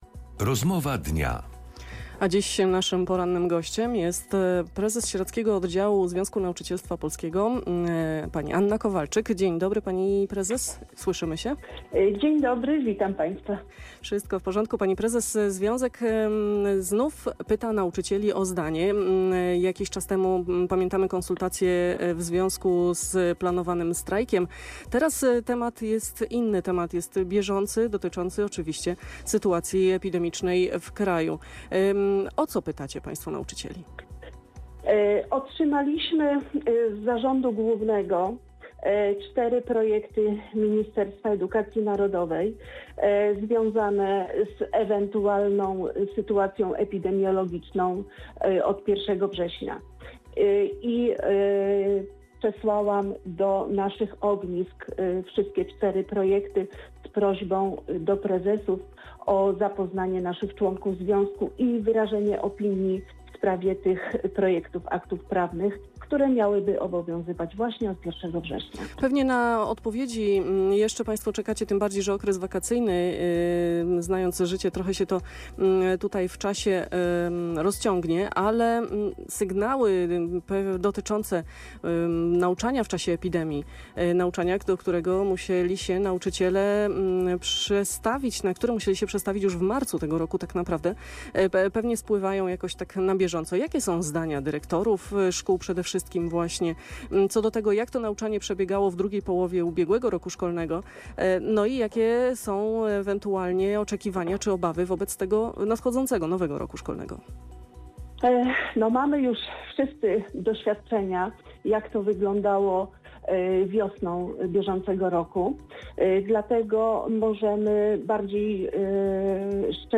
POSŁUCHAJ CAŁEJ ROZMOWY Z PORANNYM GOŚCIEM RADIA ŁÓDŹ NAD WARTĄ: Nazwa Plik Autor – brak tytułu – audio (m4a) audio (oga) Warto przeczytać Śmiertelne potrącenie w Sieradzu na DK 83 2 lipca 2025 To jest temat.